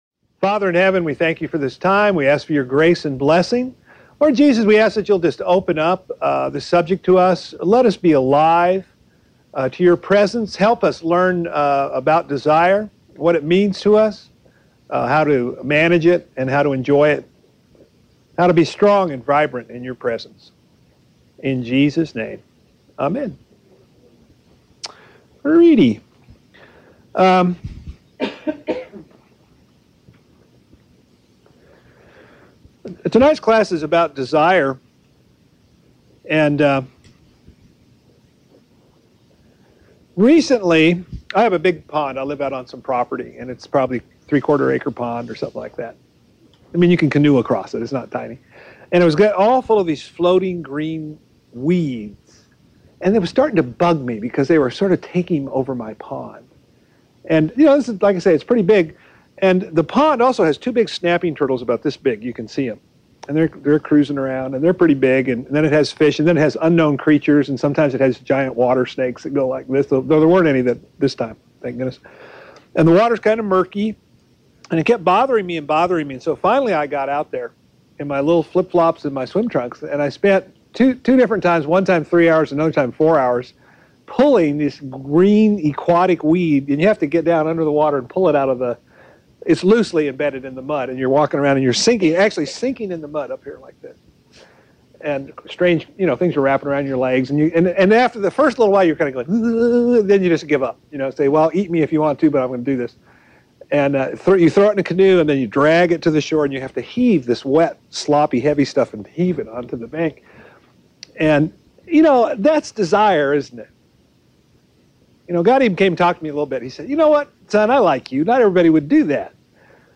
Lesson 5